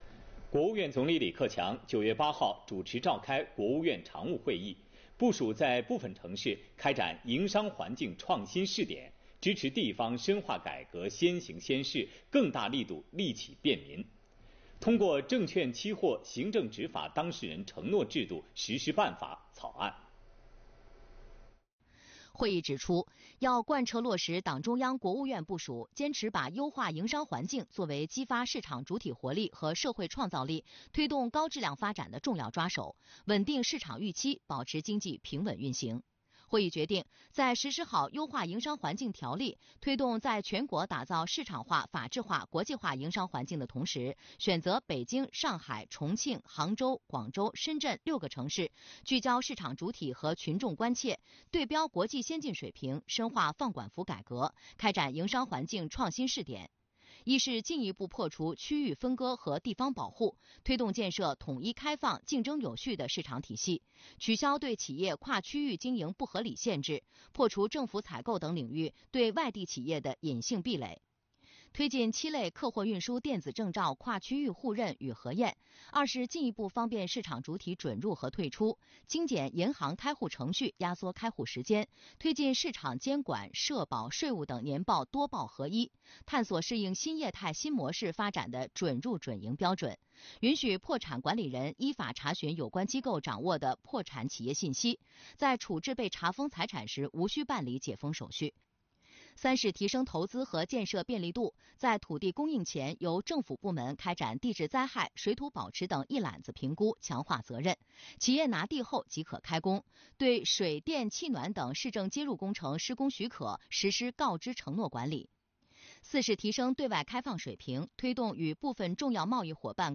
李克强主持召开国务院常务会议